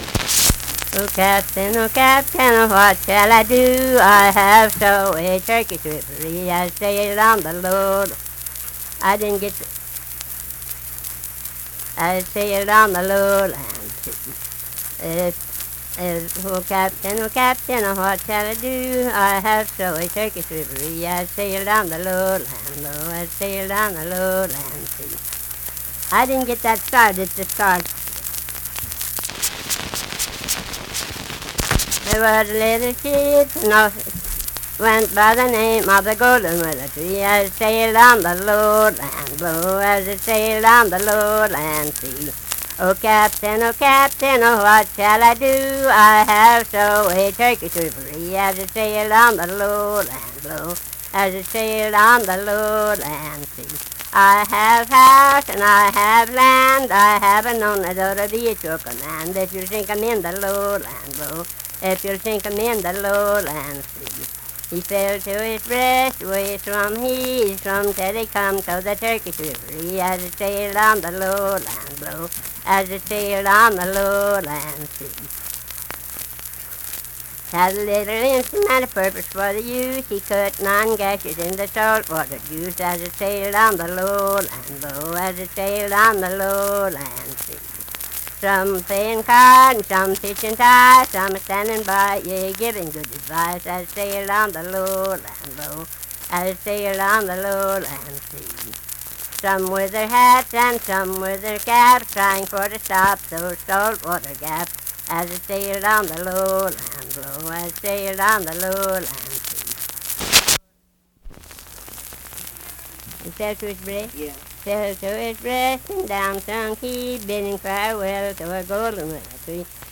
Unaccompanied vocal music
Voice (sung)
Lundale (W. Va.), Logan County (W. Va.)